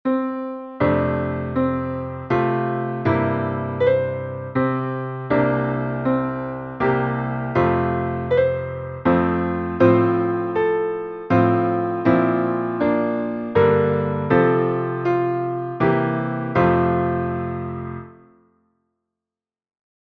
Tonalità: fa maggiore
Metro: 3/4